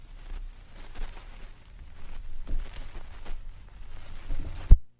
vinyl_record_needle_lift_01
click lift needle player pop record scratch vintage sound effect free sound royalty free Sound Effects